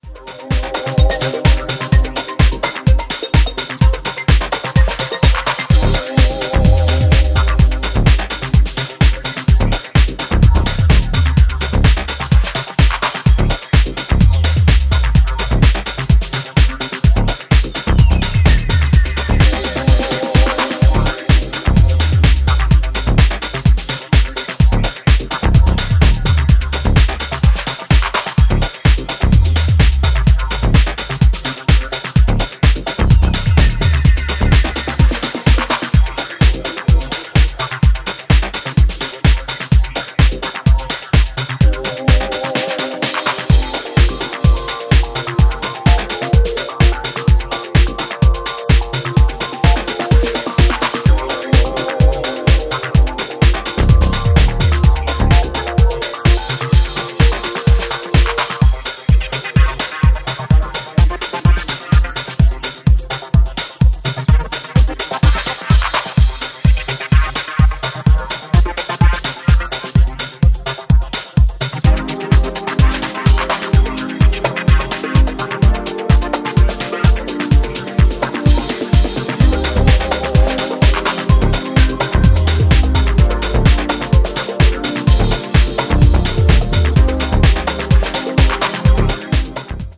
降り注ぐユーフォリックなコードとファンクするベースラインの対比が享楽的な
推進力抜群のエネルギーとサイケデリックなテクスチャーが共存した精彩なプロダクションが光ります。